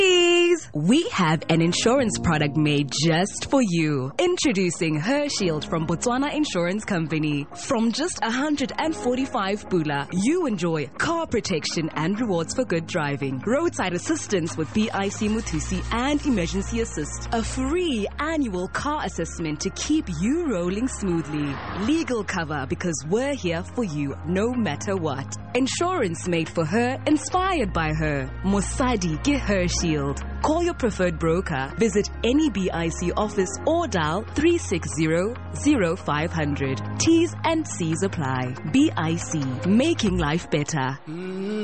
Advert: Car Insurance